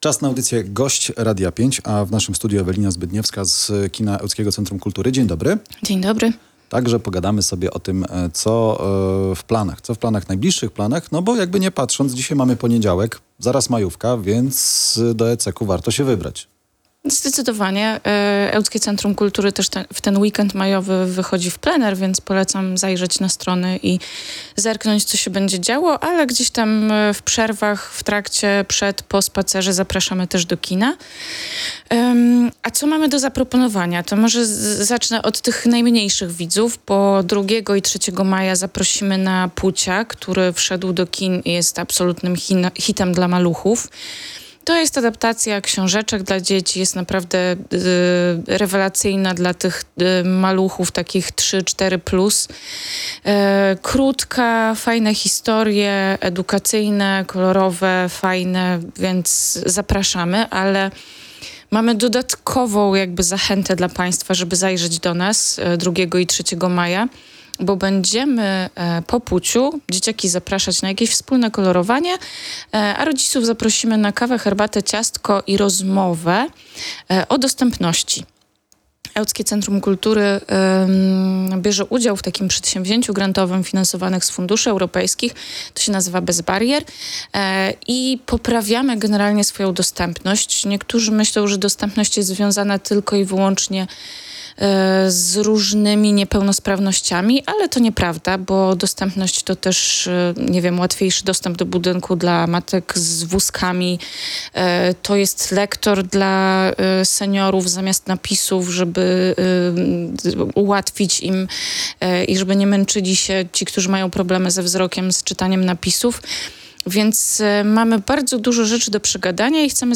O tym mówiła na antenie Radia 5